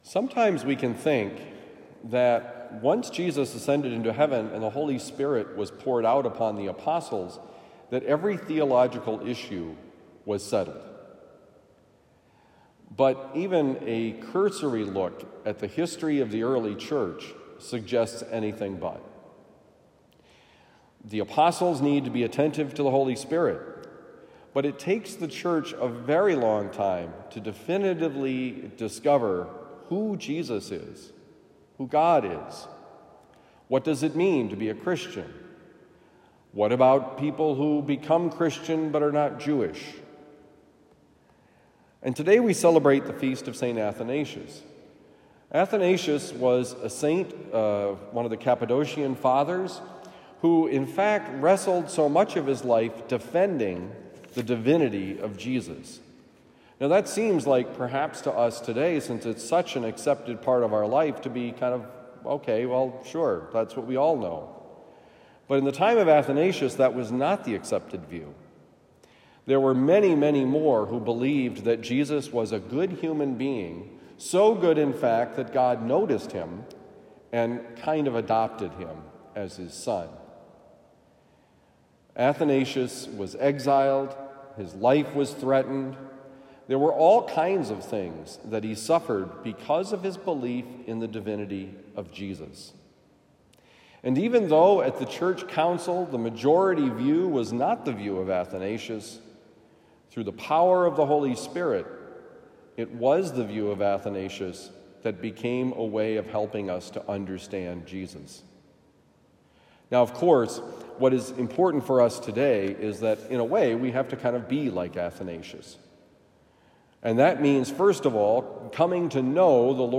Jesus is the Son of God: Homily for Tuesday, May 2, 2023
Given at Christian Brothers College High School, Town and Country, Missouri.